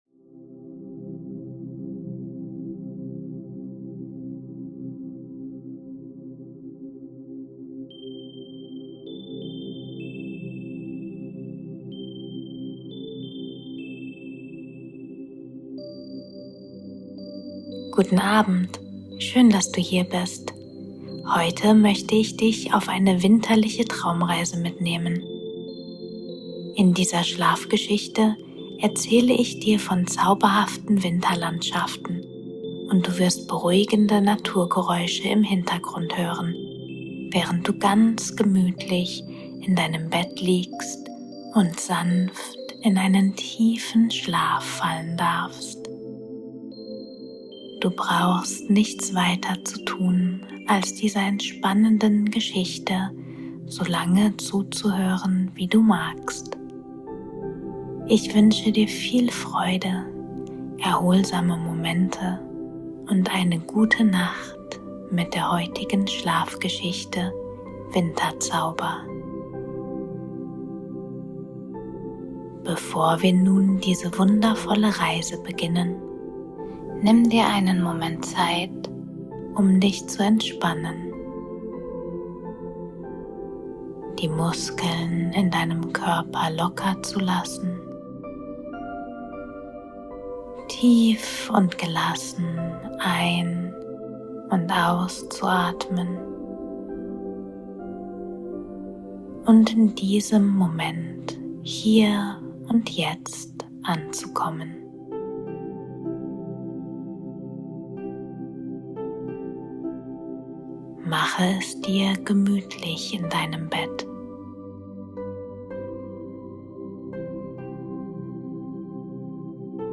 Waldzauber-Schlaf - Seelenruhe mit beruhigenden Naturklängen